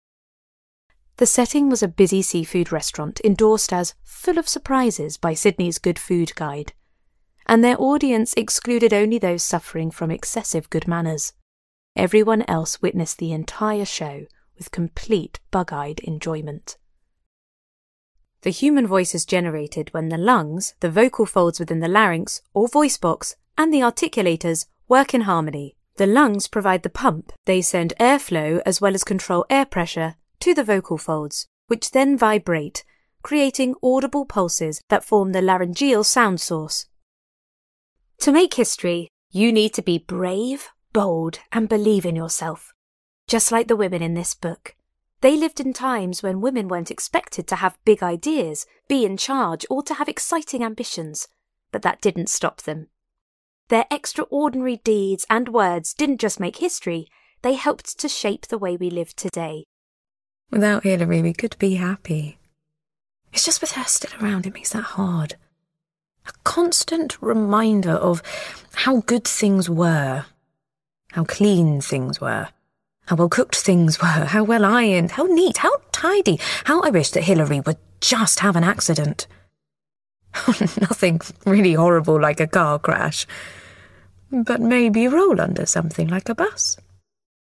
Audiobook Reel
• Native Accent: RP